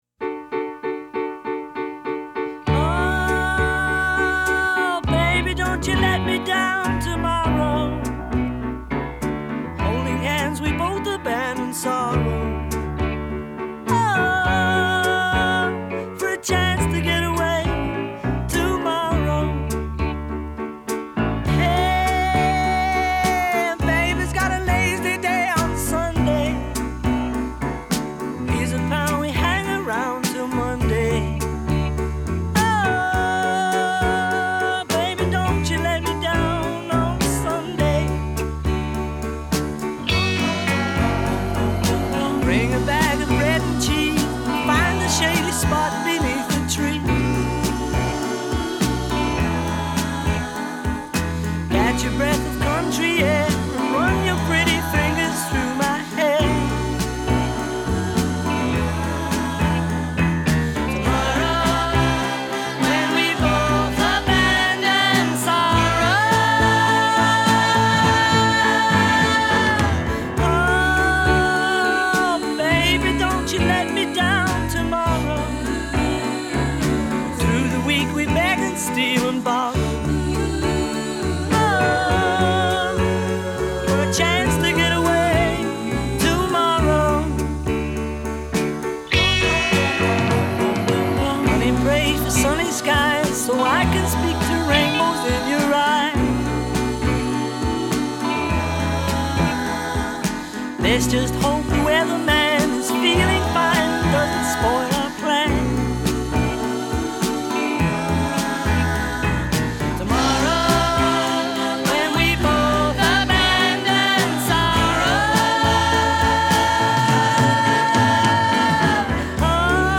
It’s pretty cheesy
the backing vocals are especially effective here